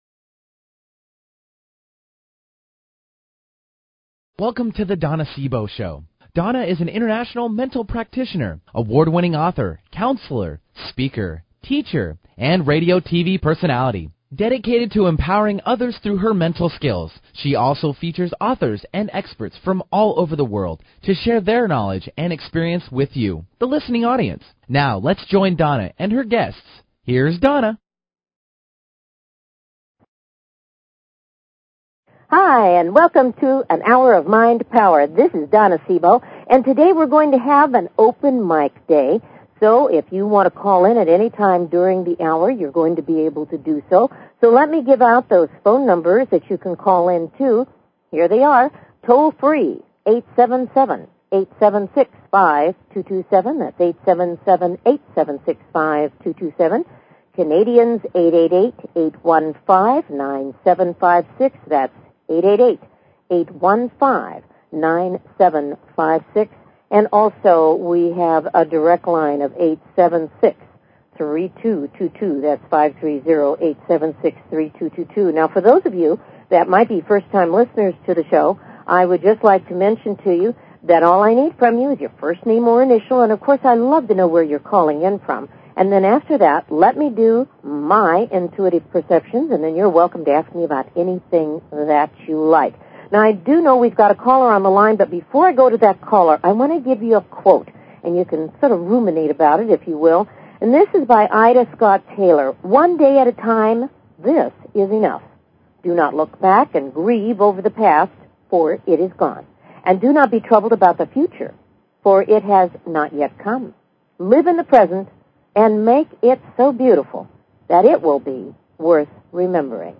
Talk Show Episode, Audio Podcast
This is an open mike day. Phone lines will be open and available during the entire hour.